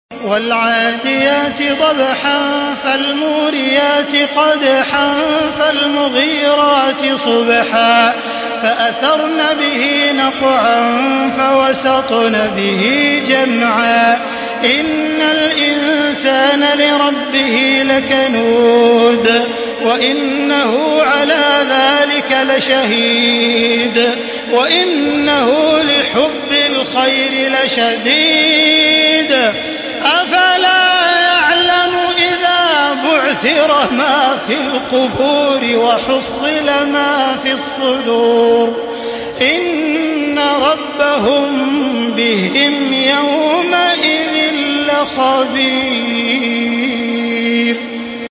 Surah Al-Adiyat MP3 in the Voice of Makkah Taraweeh 1435 in Hafs Narration
Listen and download the full recitation in MP3 format via direct and fast links in multiple qualities to your mobile phone.
Murattal